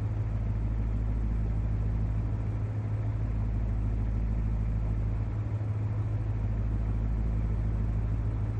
Index of /server/sound/vehicles/lwcars/merc_slk55
idle.wav